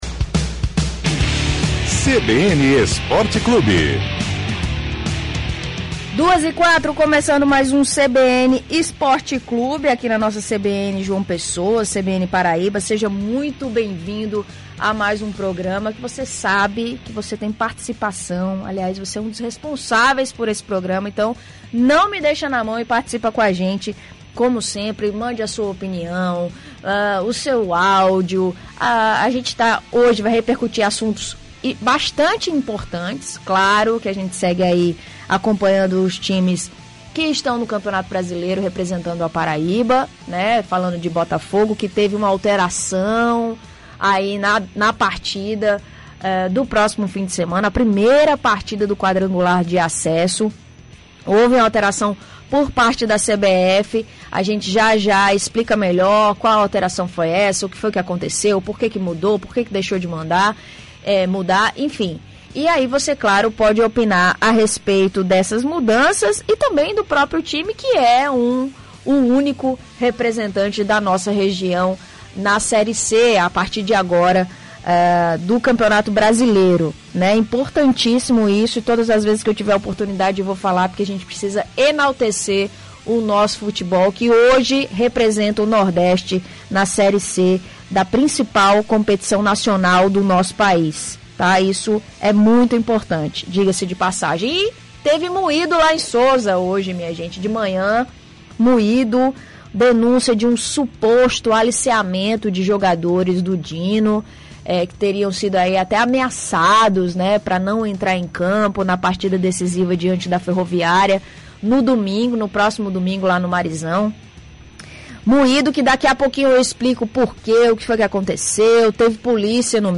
CBN Esporte Clube: entrevista com piloto de Kart – CBN Paraíba